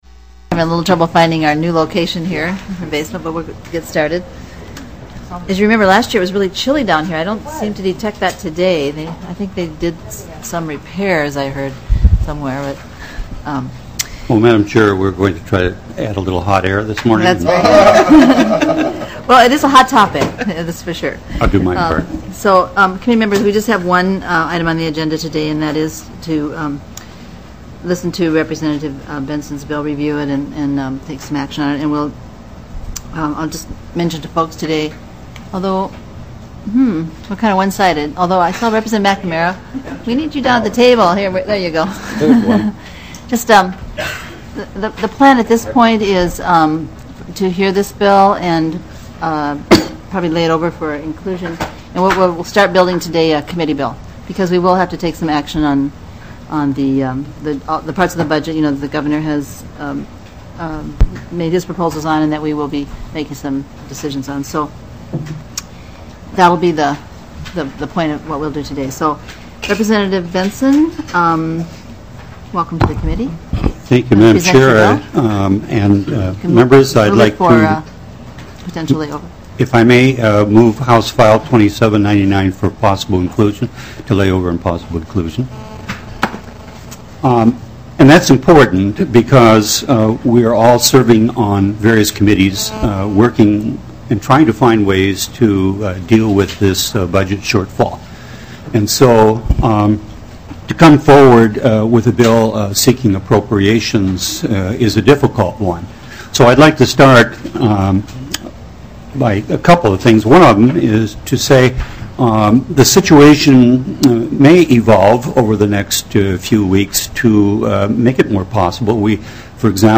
Representative Karen Clark, Chair, called the thirty-third meeting to order at 10:39 a.m. on Wednesday, February 17, 2010 in the Basement Hearing Room of the State Office Building.